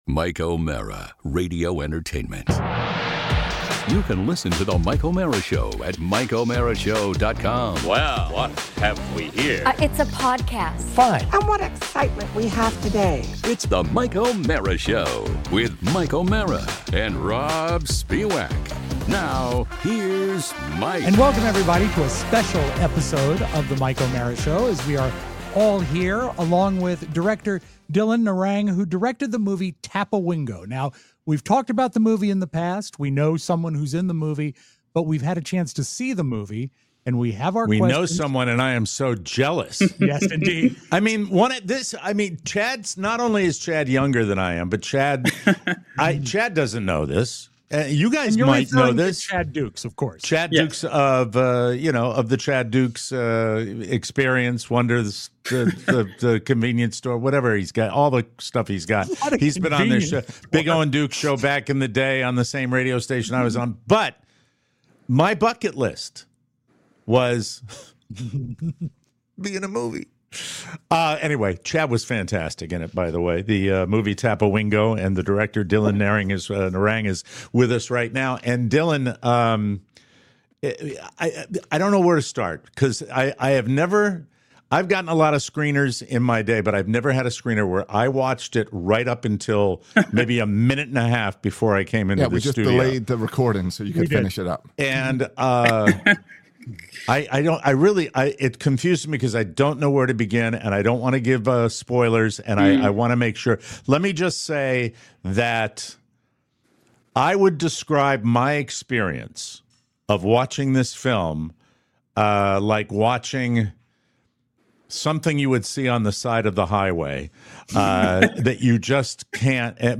A fun, insightful conversation you won’t want to miss!